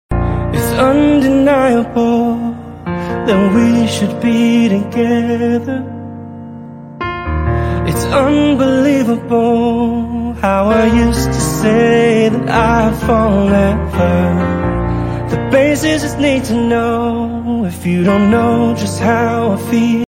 piano acoustic cover